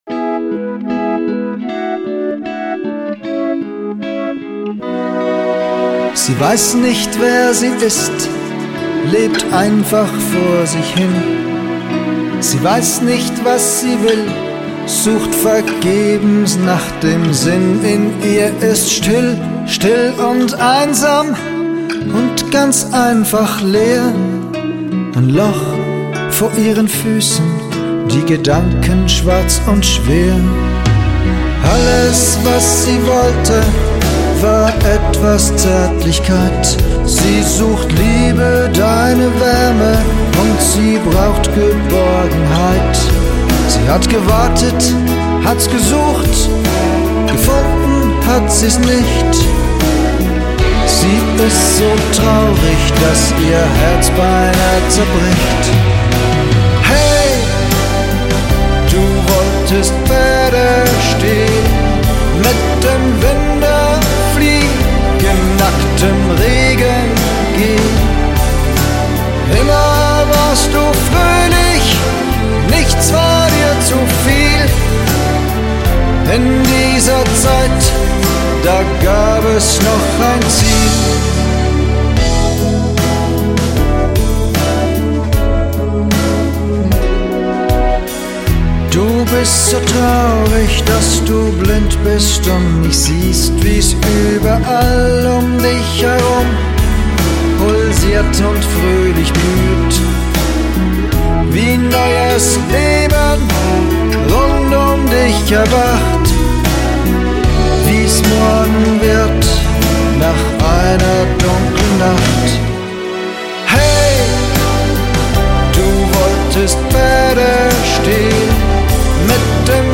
Band sucht Gitarrist(in) (Mundart, Pop) Zürich
Spannendes Band-Projekt (Singer-Songwriter-Pop) sucht raschmöglichst eine(n) versierte(n) Gitarrist(in) (Alter egal) mit Rhythmusgefühl, der/die Lust hat, sich in einer sehr erfahrenen Band einzubringen.